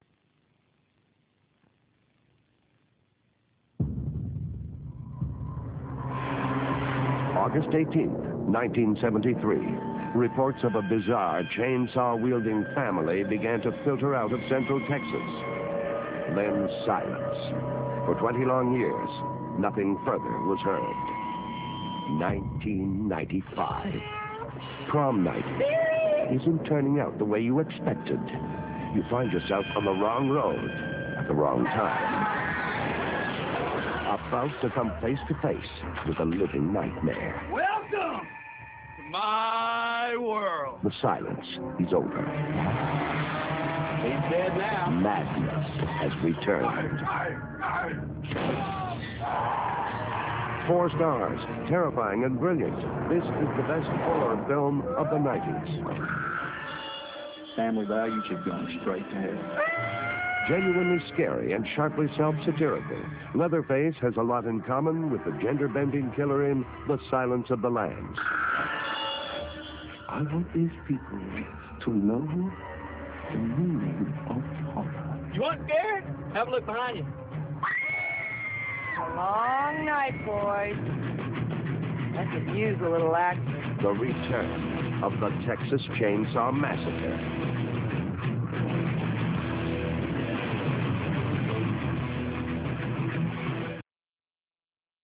Trailers